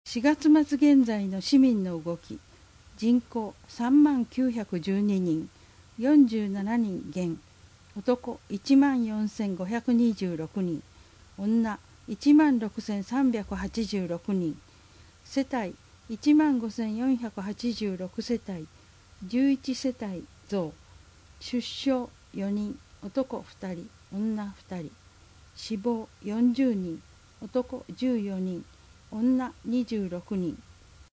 なお「声の広報」は、朗読ボランティアどんぐりの協力によって作成しています。